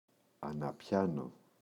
αναπιάνω [ana’pçano]